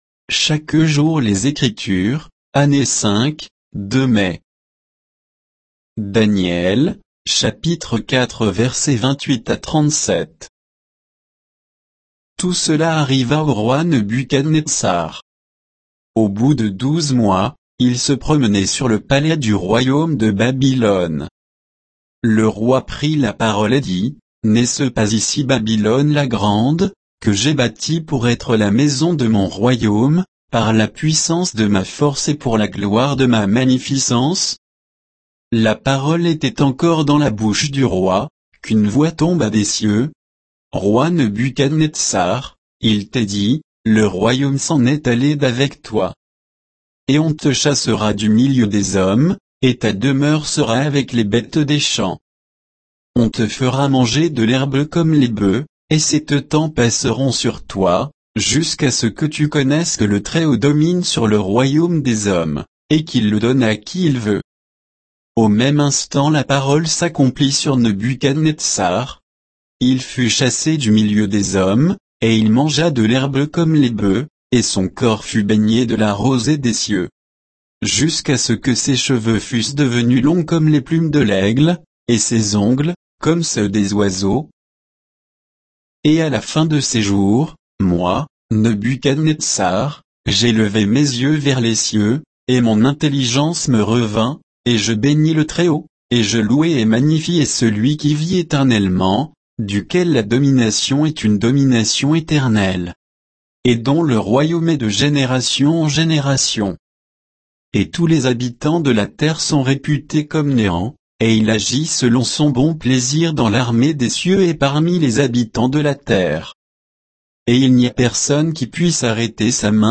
Méditation quoditienne de Chaque jour les Écritures sur Daniel 4, 28 à 37